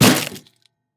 Minecraft Version Minecraft Version 1.21.5 Latest Release | Latest Snapshot 1.21.5 / assets / minecraft / sounds / mob / irongolem / damage2.ogg Compare With Compare With Latest Release | Latest Snapshot
damage2.ogg